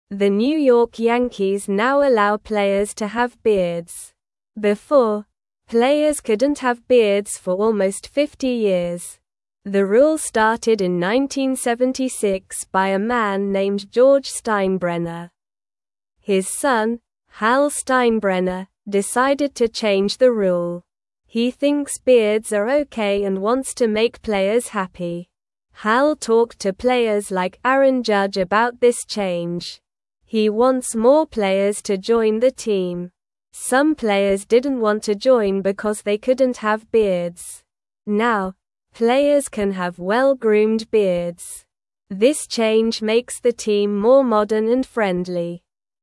Slow
English-Newsroom-Beginner-SLOW-Reading-Yankees-Players-Can-Now-Have-Beards-Again.mp3